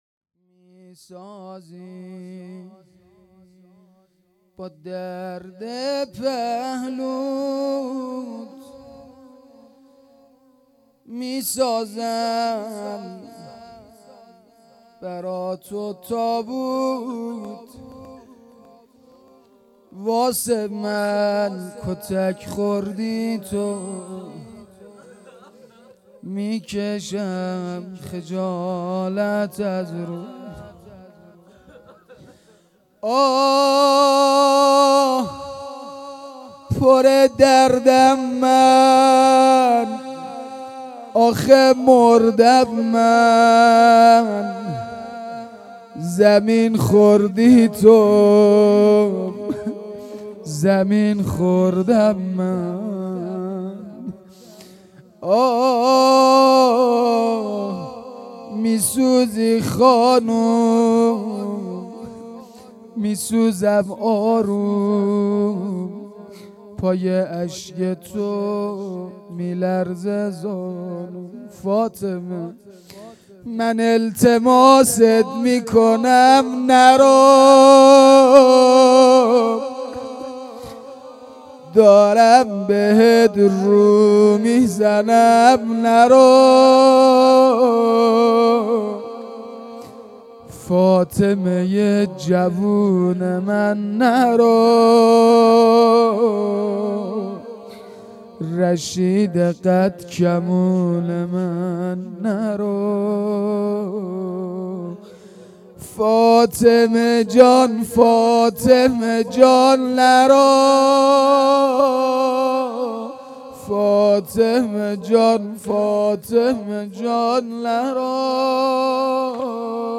شهادت حضرت فاطمه زهرا سلام الله علیها _ شب اول _ فاطمیه دوم